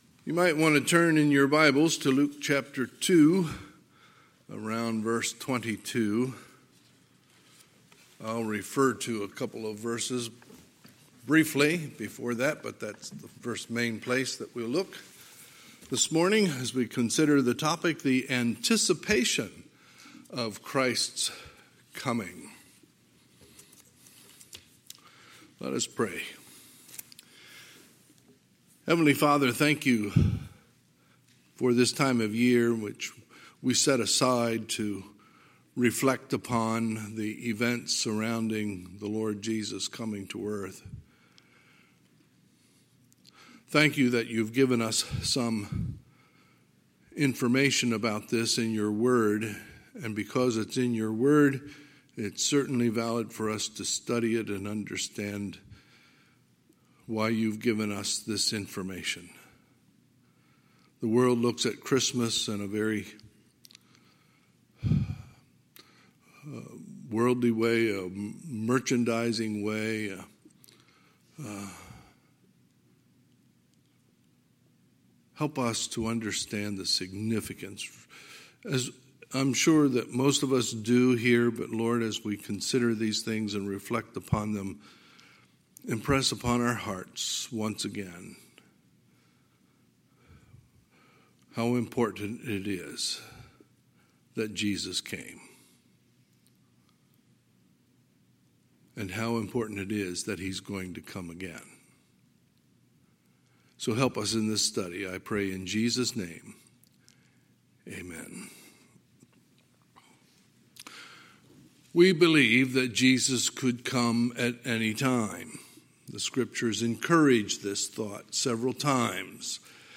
Sunday, December 6, 2020 – Sunday Morning Service